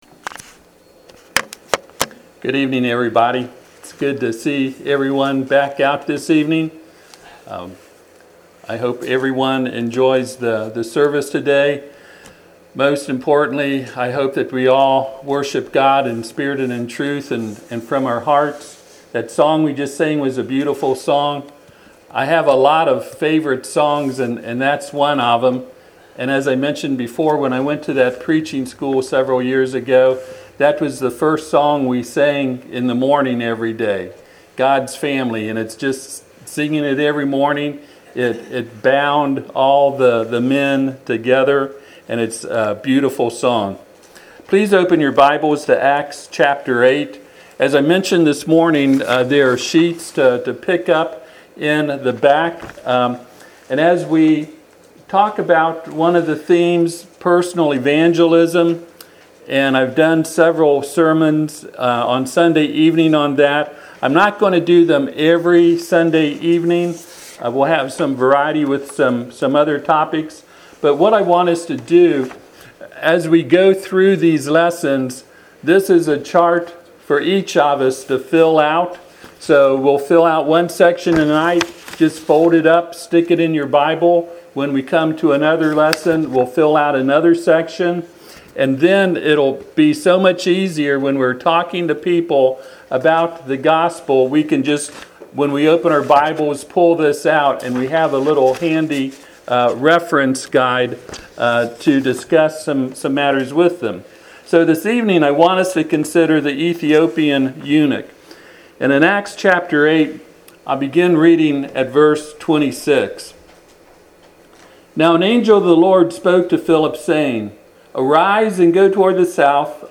Service Type: Sunday PM Topics: Baptism , Faith , Repentance , Salvation « Sermon on the Mount